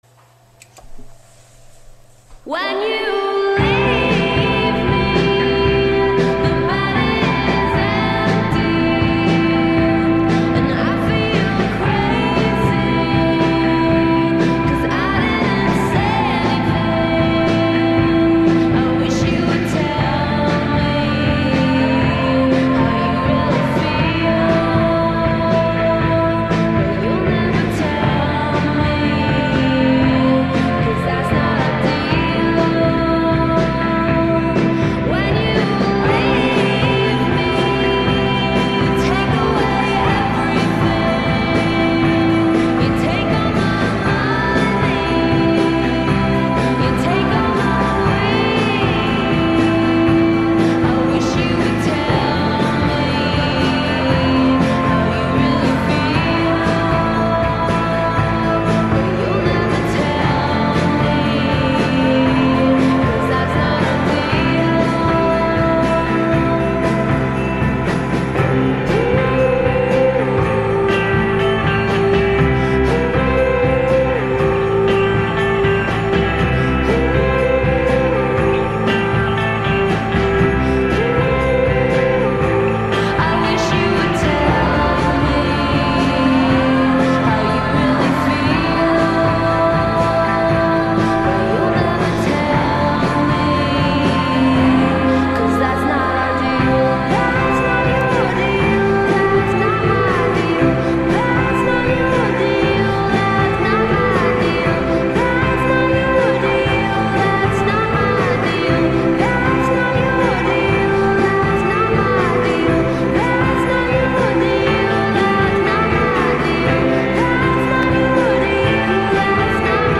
Cool 1960s reverb retro sound.